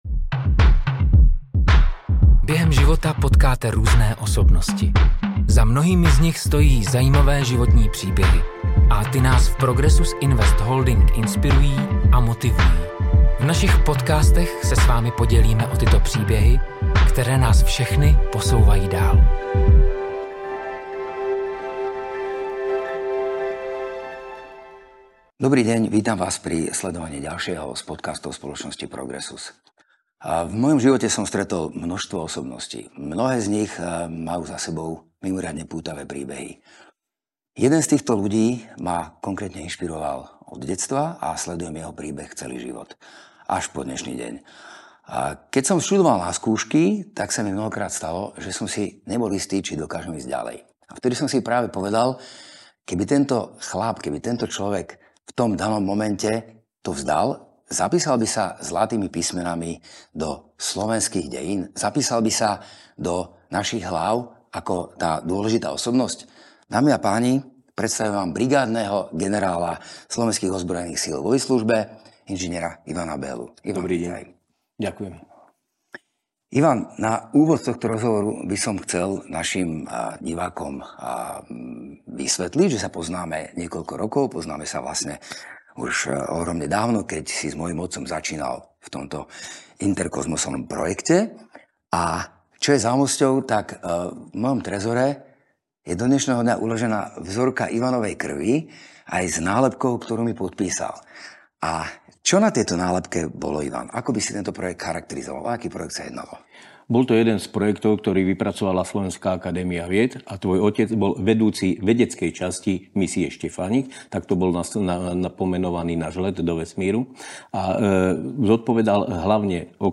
Další díl podcastu přináší rozhovor s kosmonautem panem Ivanem Bellou